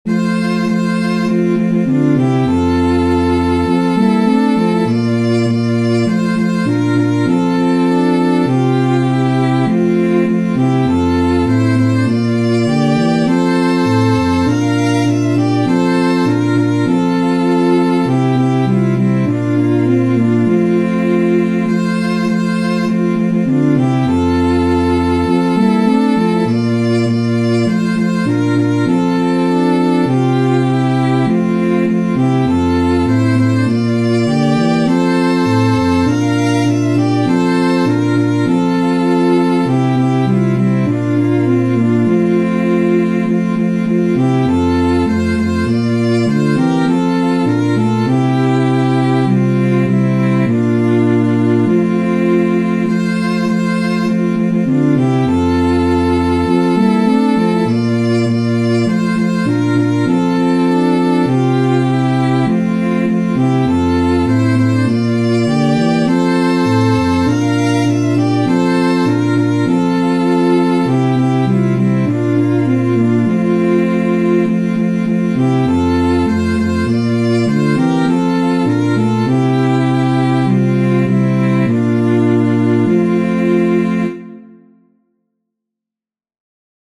Chants d’Acclamations.